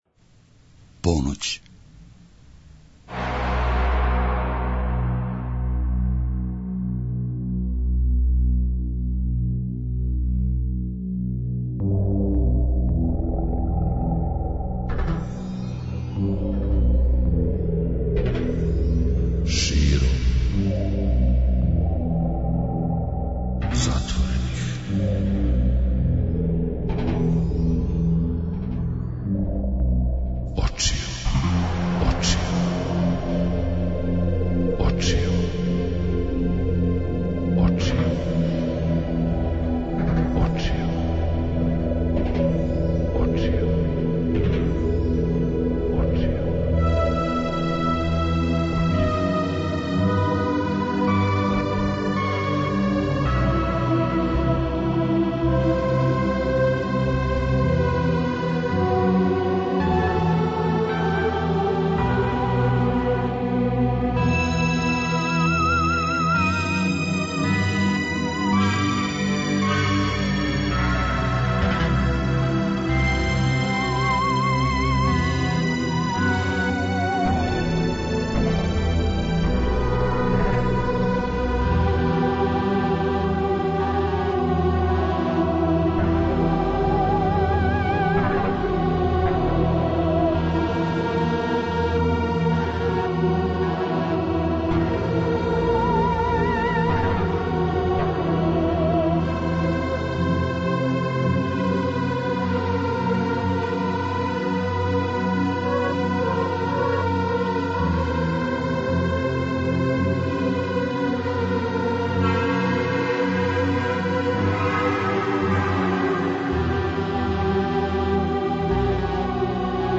Лига 202 је такмичење у којем се групе, односно извођачи такмиче за титулу шампиона, по угледу на лигашка такмичења у екипним спортовима. Свако коло ће имати парове који ће се „борити" песмама, а поготке постижете ви, својим гласовима.
преузми : 57.25 MB Широм затворених очију Autor: Београд 202 Ноћни програм Београда 202 [ детаљније ] Све епизоде серијала Београд 202 Говор и музика Састанак наше радијске заједнице We care about disco!!!